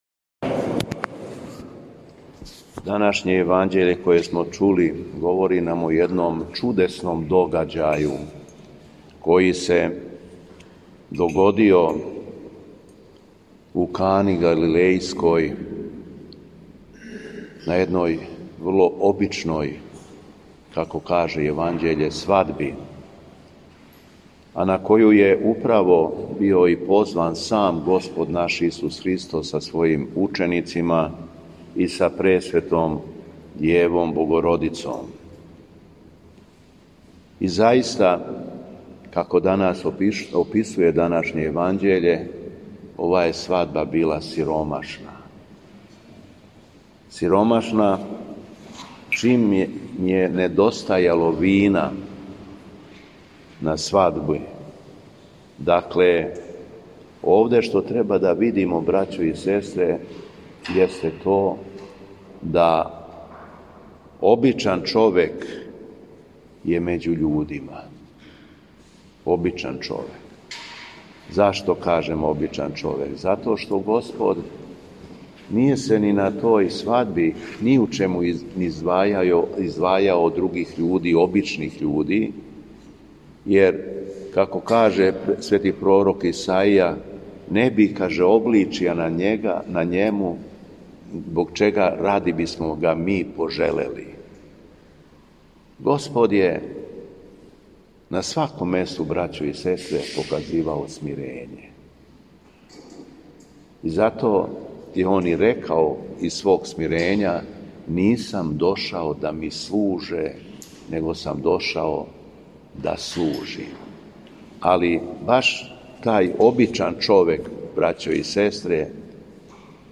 Беседа Његовог Високопреосвештенства Митрополита шумадијског г. Јована
У понедељак недеље Антипасхе, Његово Високопреосвештенство митрополит шумадијски Господин Јован служио је Свету архијерејску Литургију у храму Светога Саве у крагујевачком насељу Аеродром.